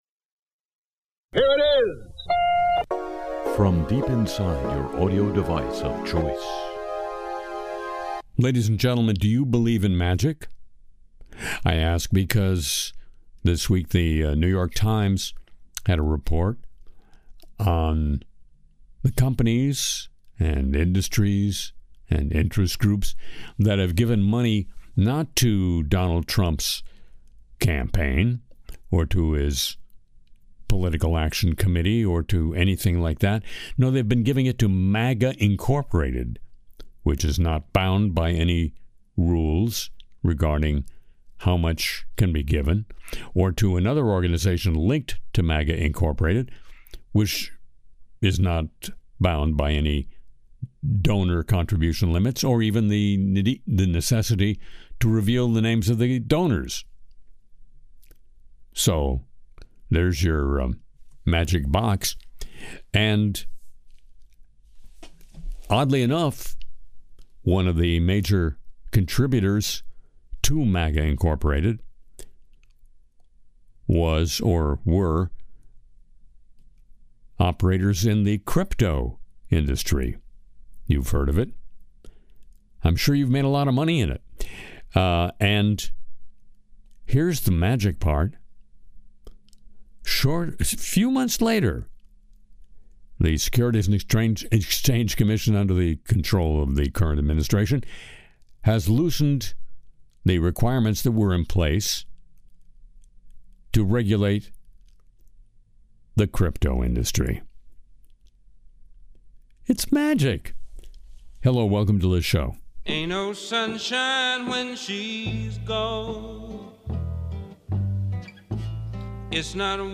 Harry Shearer delivers laughs and sharp commentary: Tom Lehrer remembered, Trump parodied, crypto chaos, Tesla’s crash ruling, Starlink vs science, and the Vatican scandal.